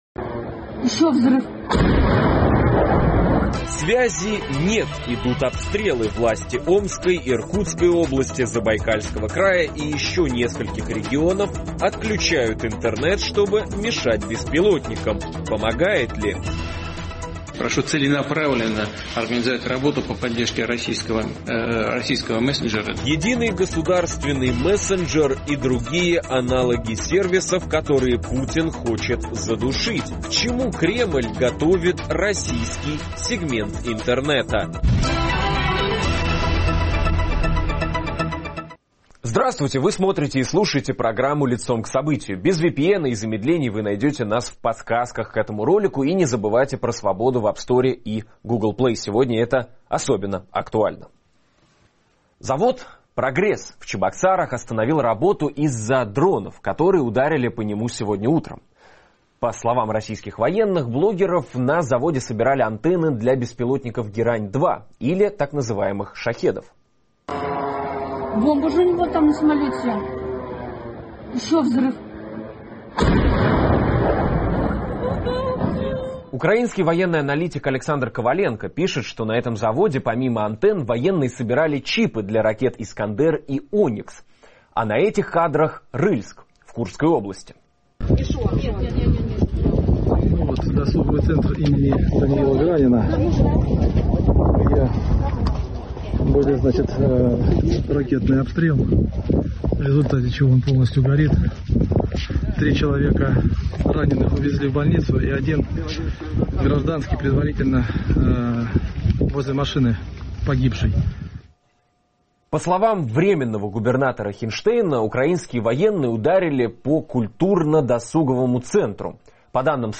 говорим с экспертом в области интернет-безопасности